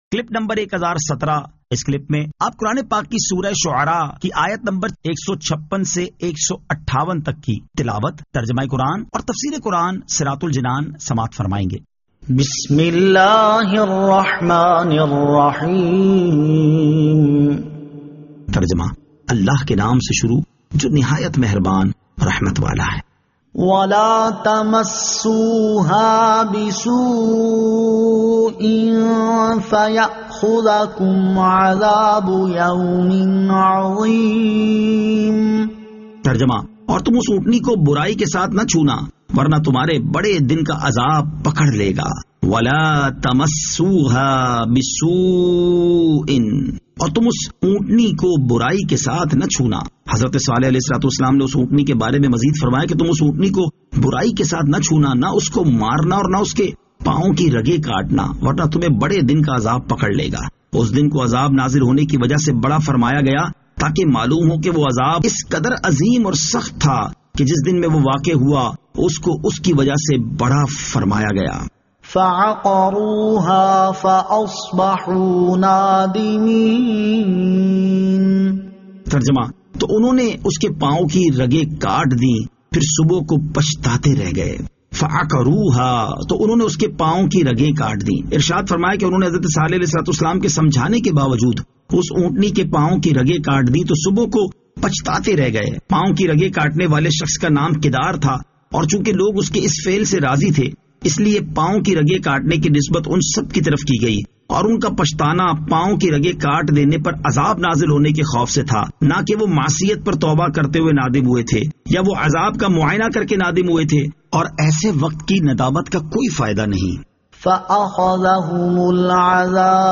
Surah Ash-Shu'ara 156 To 158 Tilawat , Tarjama , Tafseer